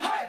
crowdHai1.wav